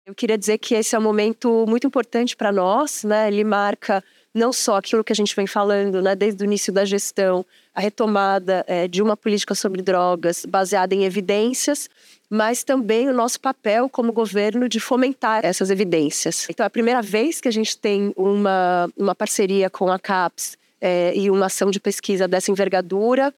Secretária Marta Machado afirma que lançamento do Procad reforça o papel do governo de fomentar políticas baseadas em evidências.mp3 — Ministério da Justiça e Segurança Pública